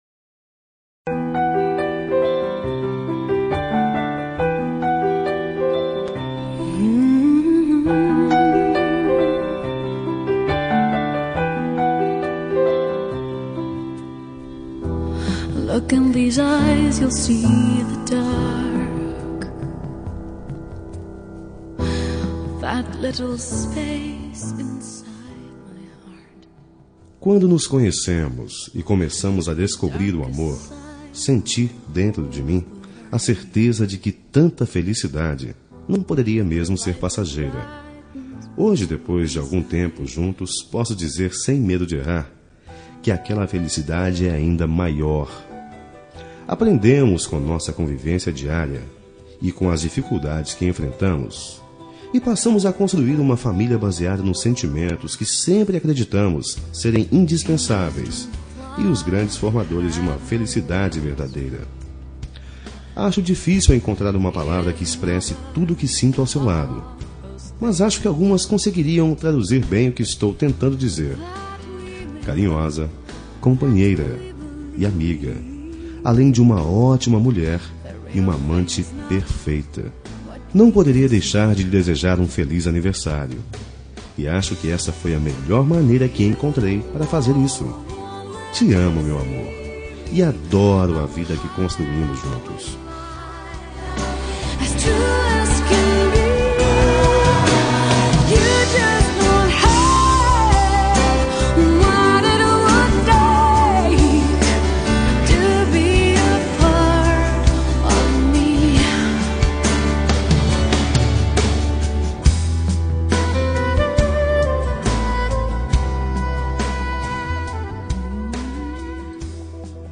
Telemensagem de Aniversário de Esposa – Voz Masculina – Cód: 1119 Linda